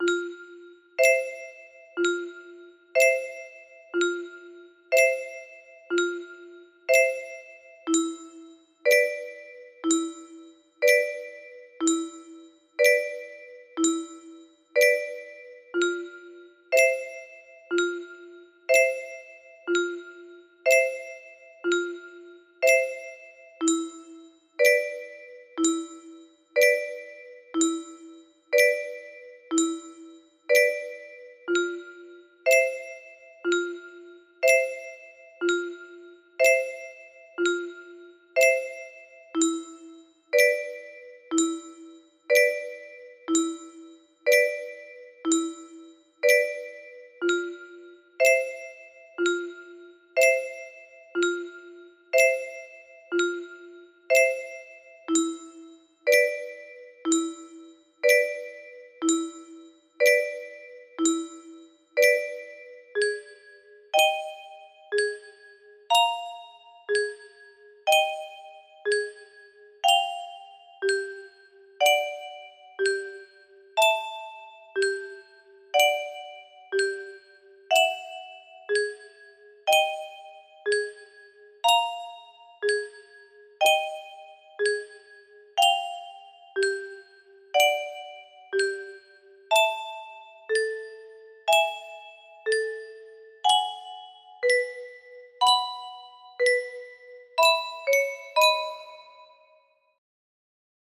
Track 7 MIDI out #6 music box melody